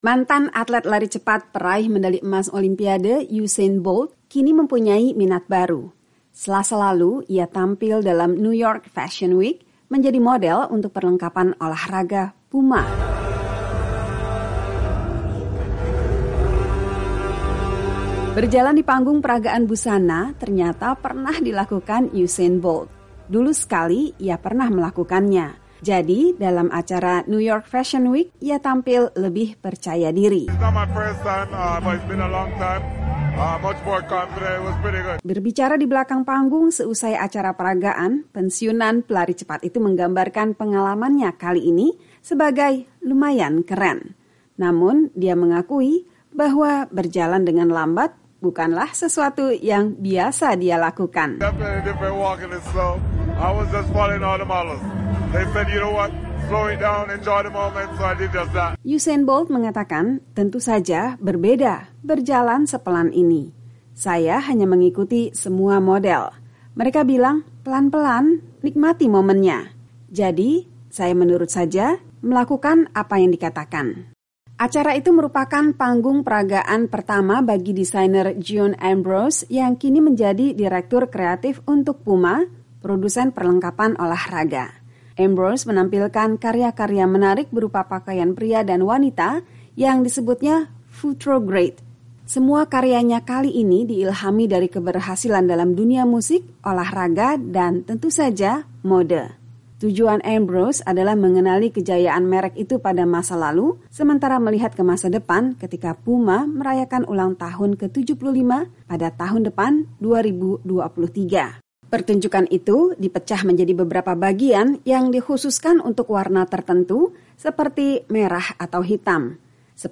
Selasa lalu ia tampil dalam New York Fashion Week, menjadi model untuk perlengkapan olah raga Puma. Simak pengalamannya dalam laporan berikut.